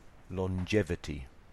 You are going to hear an interview